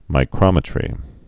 (mī-krŏmĭ-trē)